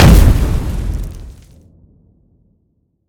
small-explosion-4.ogg